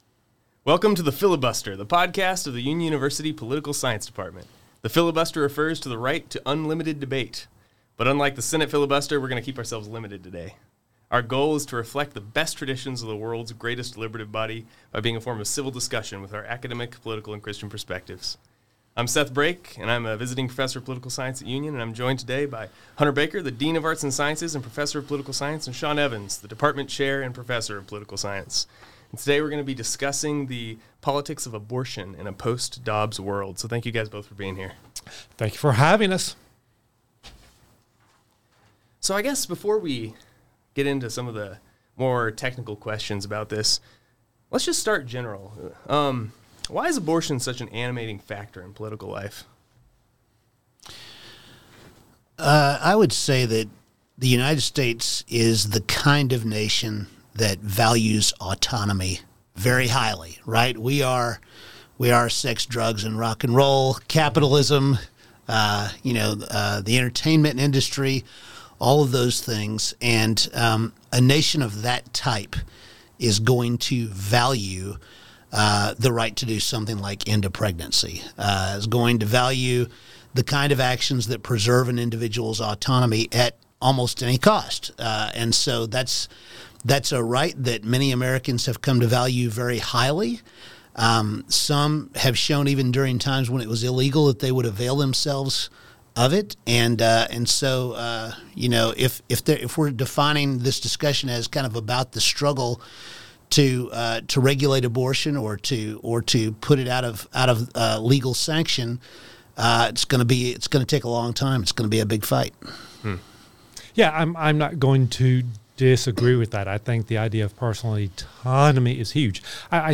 The faculty discuss how the politics of abortion has changed from the Roe (constitutional right to abortion) to the Dobbs (states can freely regulate or ban abortion) eras.